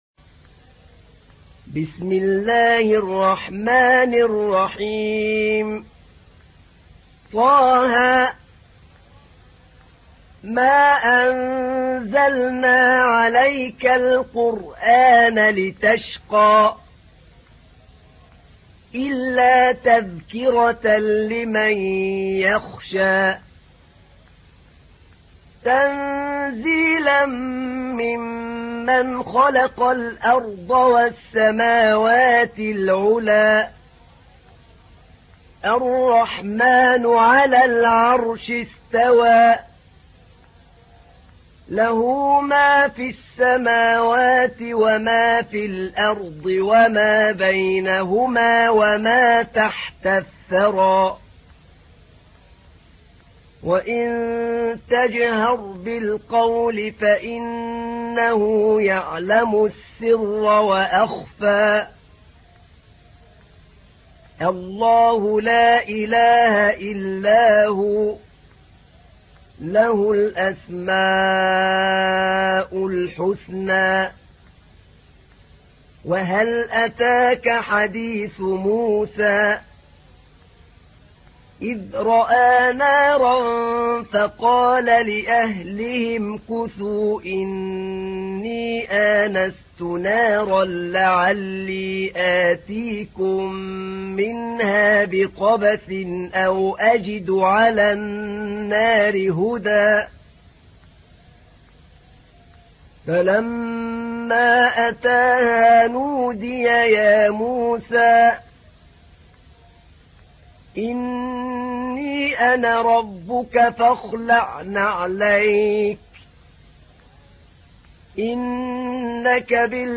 تحميل : 20. سورة طه / القارئ أحمد نعينع / القرآن الكريم / موقع يا حسين